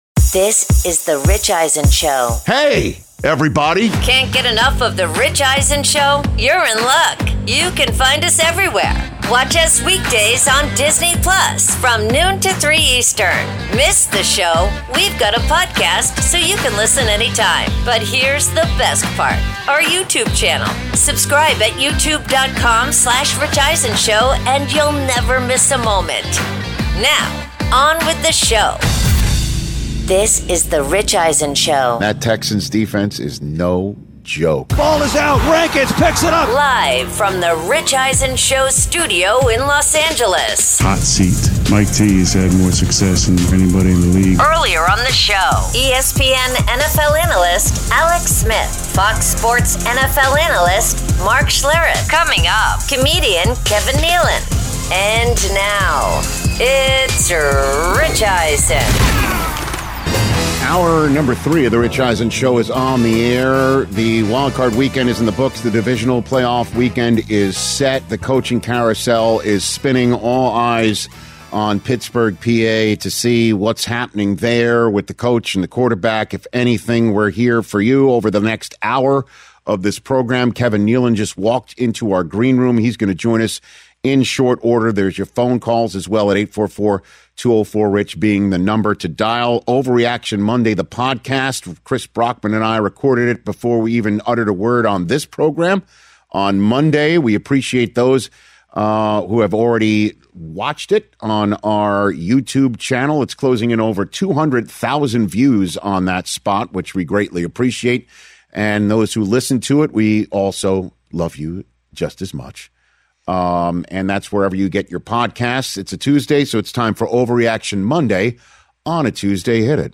Hour 3: Mike Tomlin Steps Down Live Reaction, plus Kevin Nealon In-Studio